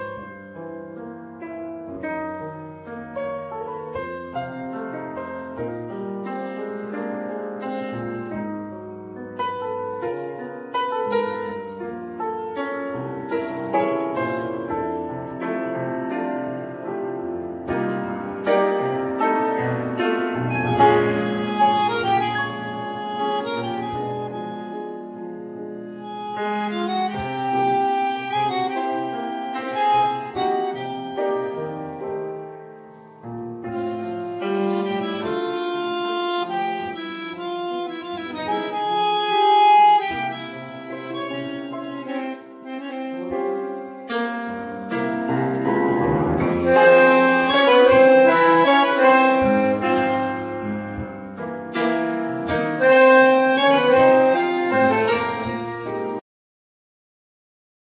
Piano
Double bass
Drums
Ac guitar
Bandoneon